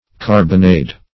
carbonade - definition of carbonade - synonyms, pronunciation, spelling from Free Dictionary
Carbonade \Car"bo*nade\, Carbonado \Car`bo*na"do\, n. [Cf. F.